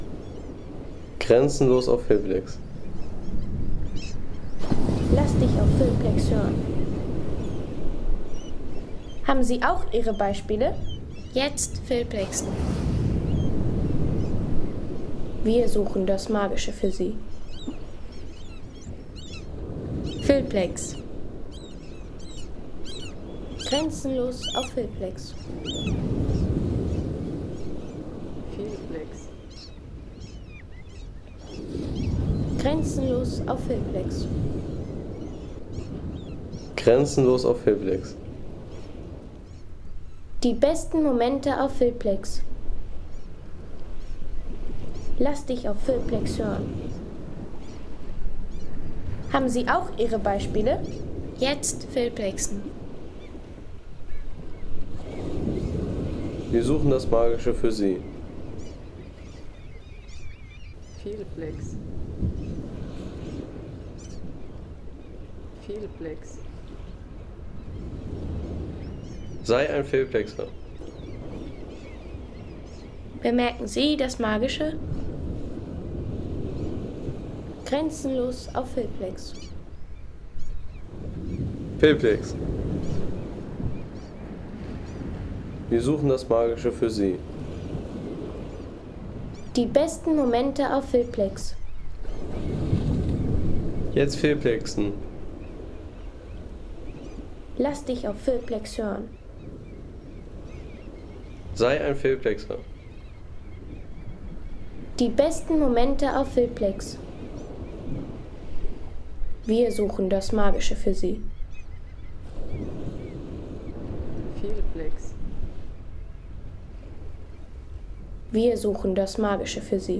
Kraftvoller Küsten-Sound aus Hemnessand in Norwegen mit Möwenrufen und Wellen an rauer Granitküste.
Kraftvolle Wellen, raue Granitküste und Möwenrufe aus Hemnessand. Ein lebendiger Küstensound für Film, Postkarten und Hintergrundszenen.